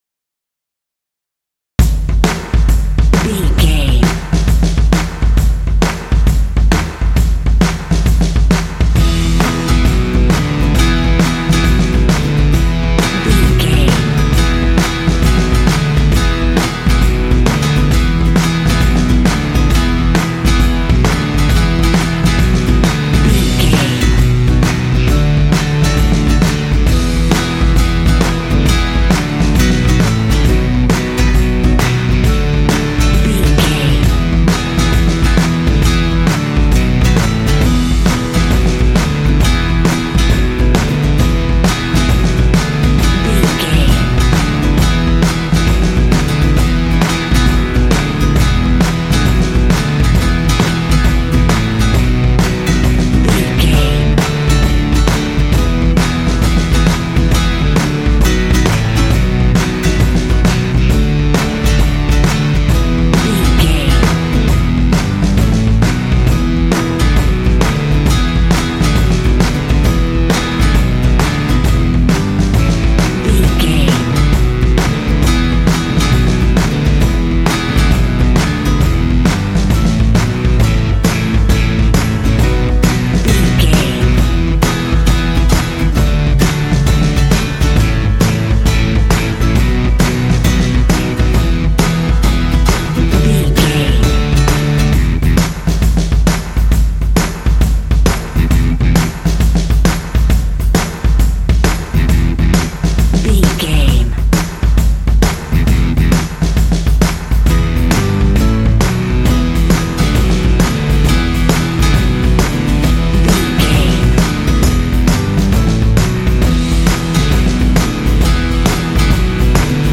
Aeolian/Minor
groovy
intense
driving
energetic
drums
percussion
electric guitar
bass guitar
acoustic guitar
classic rock
alternative rock